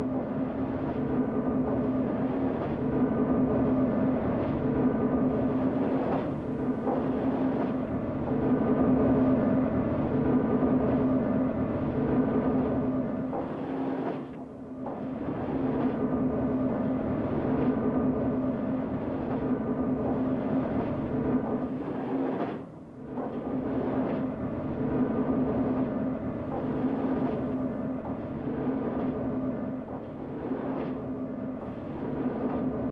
agricultural-tower-hub-loop.ogg